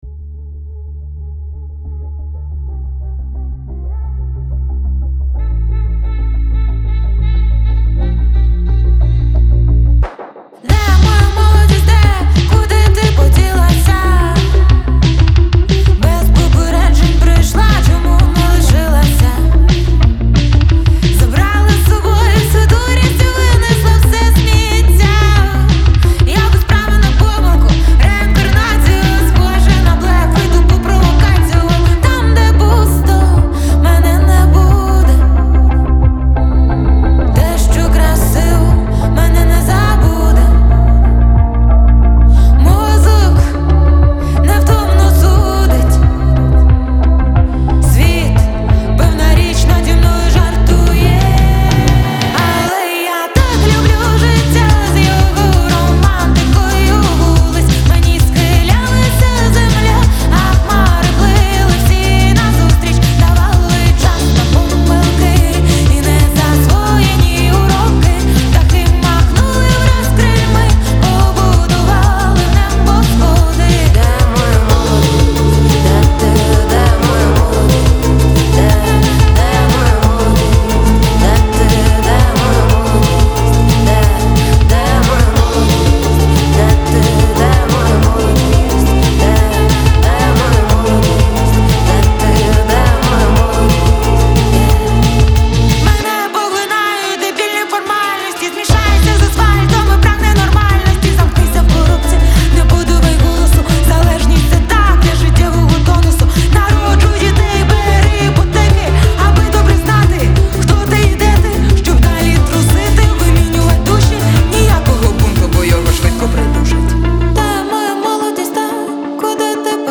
• Жанр: Pop, Indie